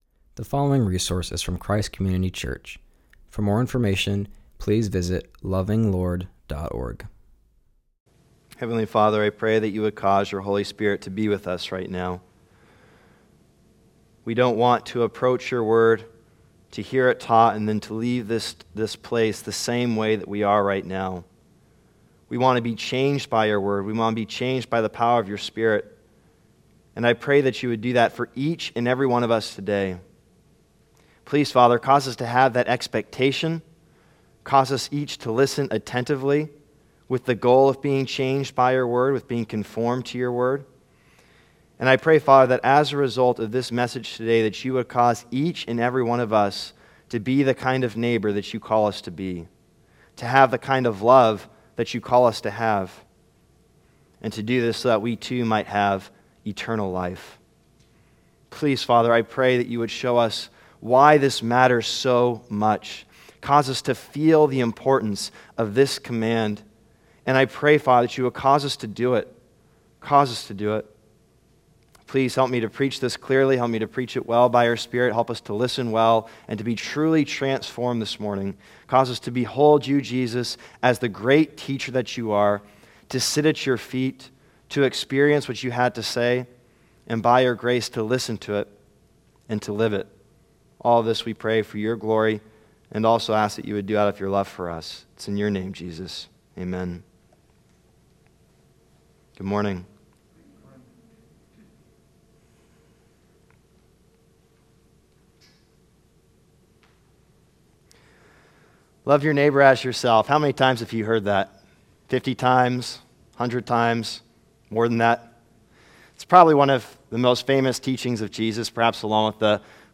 Love Your Neighbor - Luke 10:25-37 | Christ Community Church of San Jose